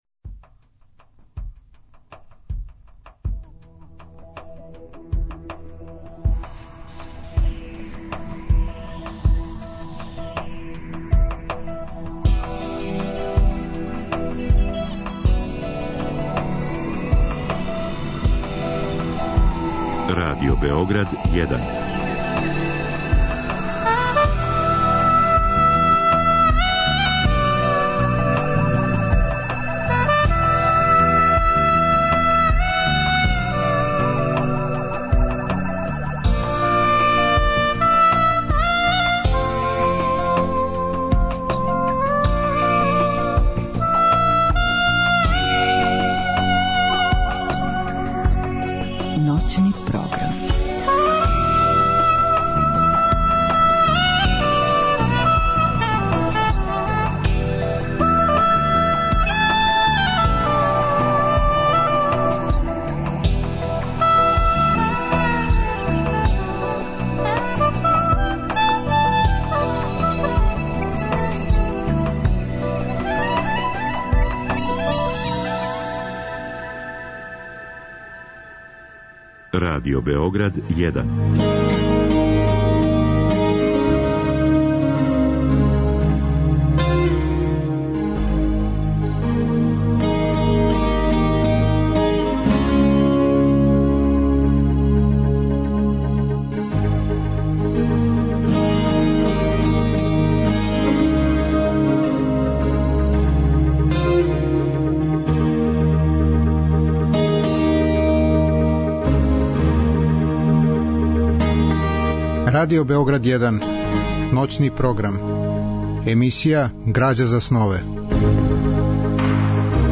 Разговор и добра музика требало би да кроз ову емисију и сами постану грађа за снове.
У другом делу емисије, од два до четири часa ујутро, слушаћемо одабране делове радио-драме Острво папомахниташа Франсоа Раблеа, која је реализована 1993. године у продукцији Драмског програма Радио Београда.